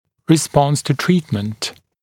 [rɪ’spɔns tu ‘triːtmənt][ри’спонс ту ‘три:тмэнт]ответная реакция на лечение